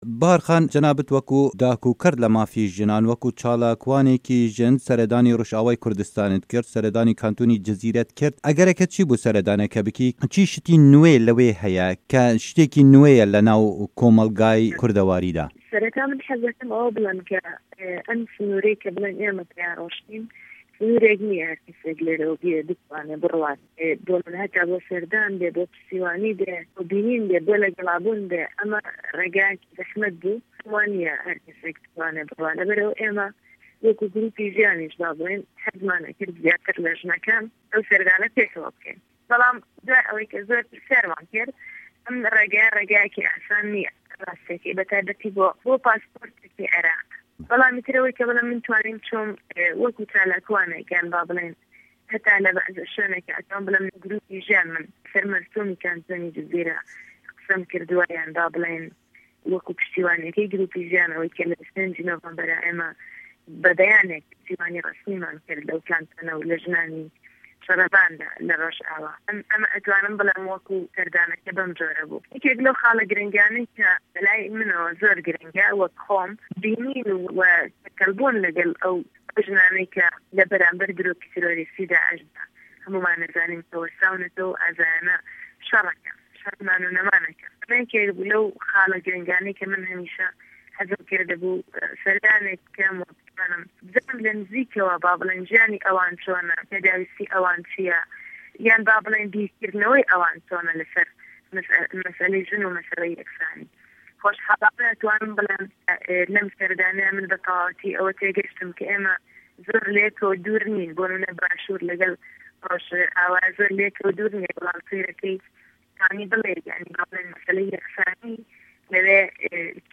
hevpeyvin